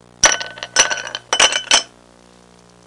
Ice Cubes In Glass Sound Effect
Download a high-quality ice cubes in glass sound effect.
ice-cubes-in-glass.mp3